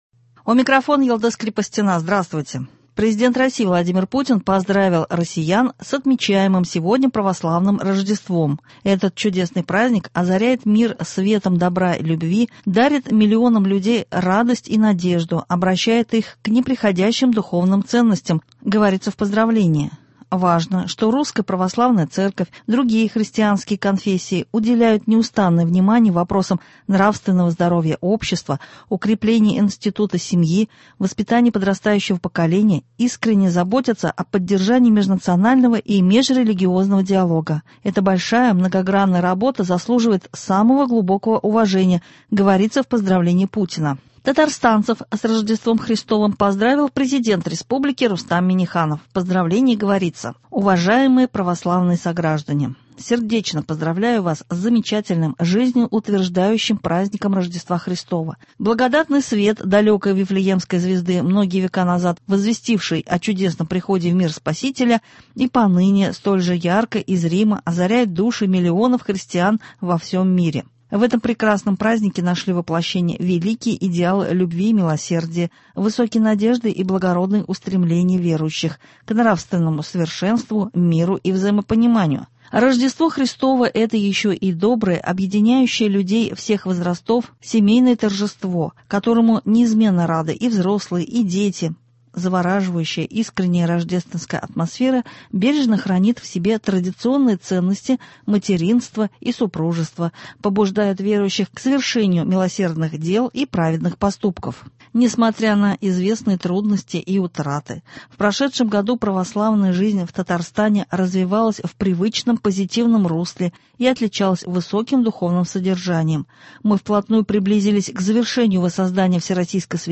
Новости (07.01.21) | Вести Татарстан